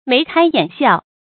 眉開眼笑 注音： ㄇㄟˊ ㄎㄞ ㄧㄢˇ ㄒㄧㄠˋ 讀音讀法： 意思解釋： 眉頭舒展；眼含笑意。形容十分高興的樣子。